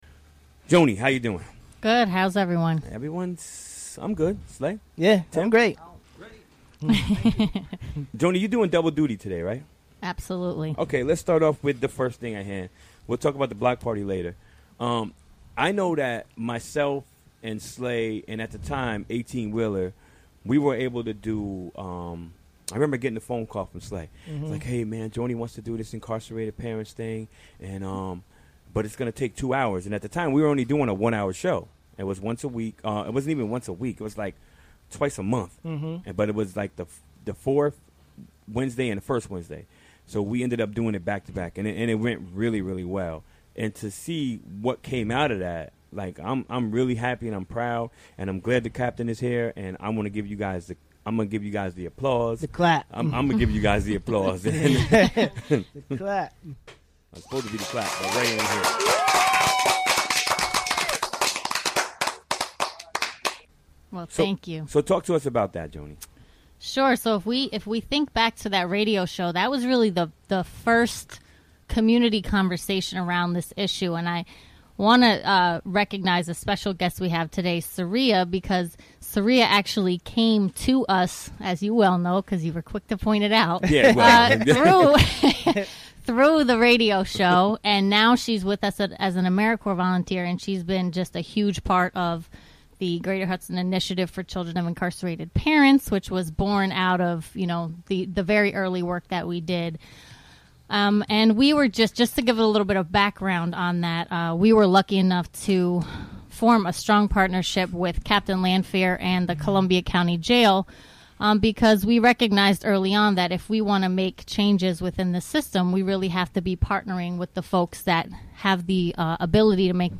Recorded during the WGXC Afternoon Show Wednesday, August 24, 2016.